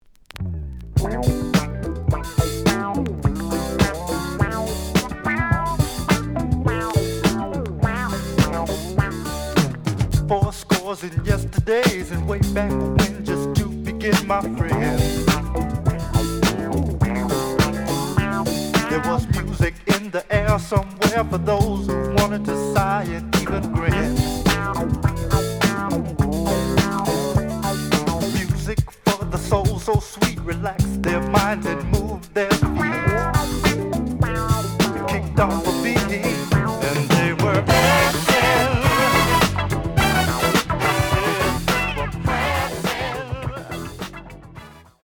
The audio sample is recorded from the actual item.
●Format: 7 inch
●Genre: Funk, 70's Funk